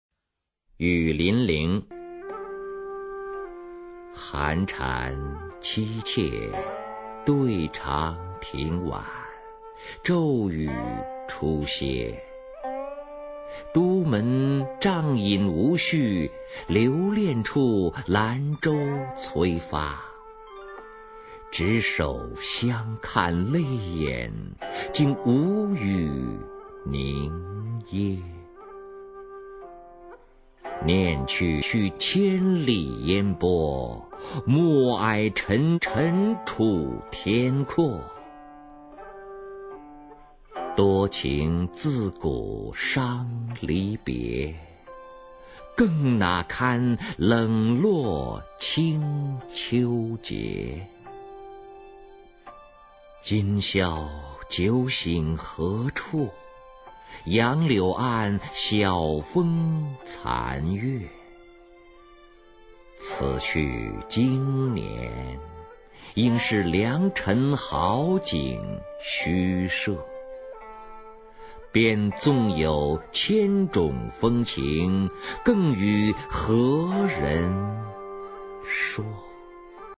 [宋代诗词朗诵]柳永-雨霖铃 古诗词诵读